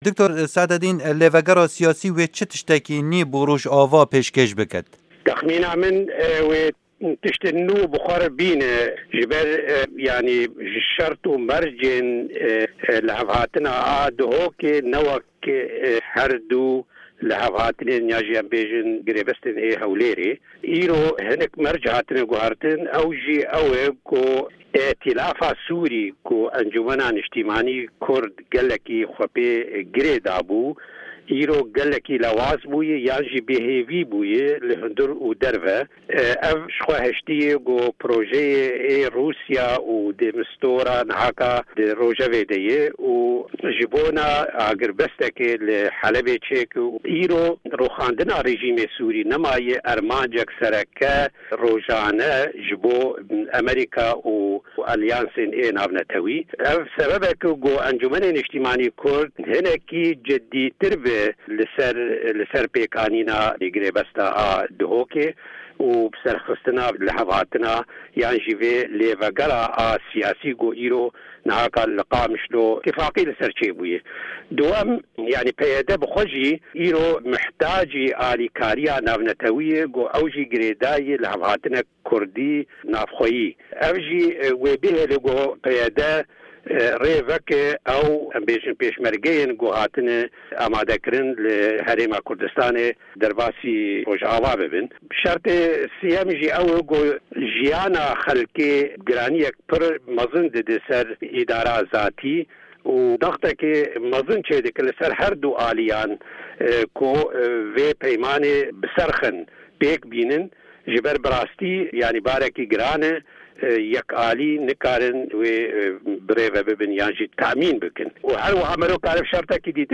hevpeyvin